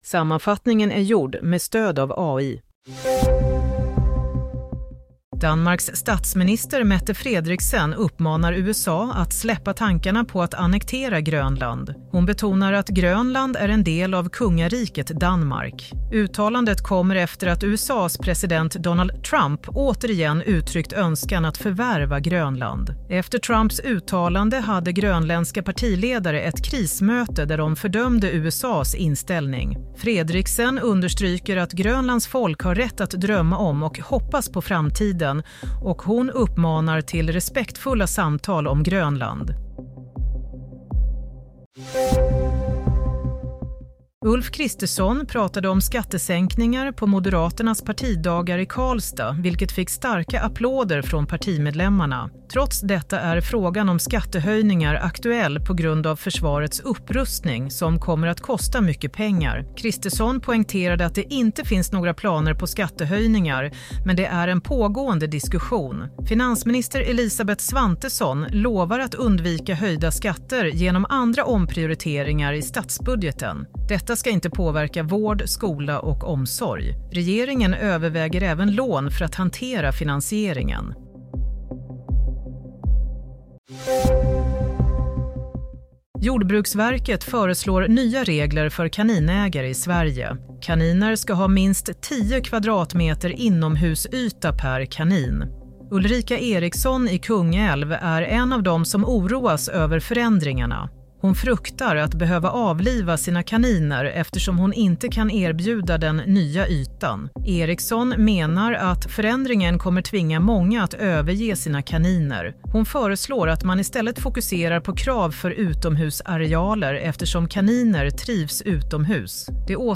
Nyhetssammanfattning - 15 mars 07:00